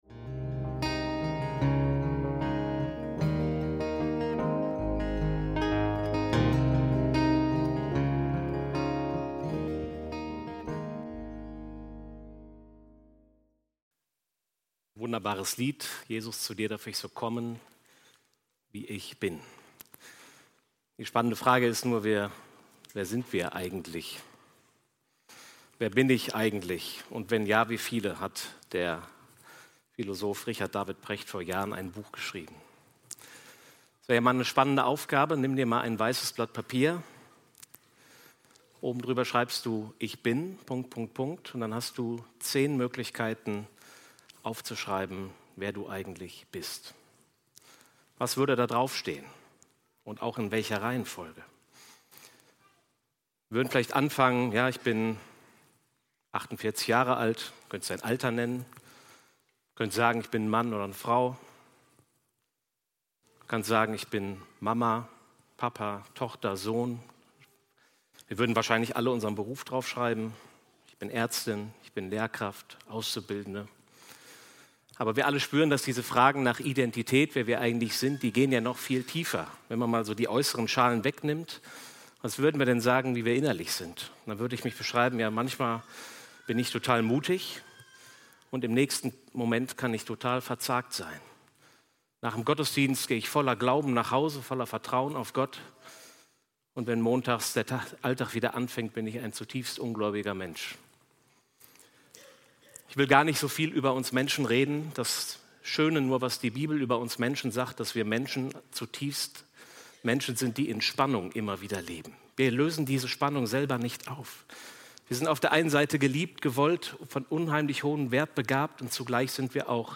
Vom Brot des Lebens essen und trinken ~ FeG Bochum Predigt Podcast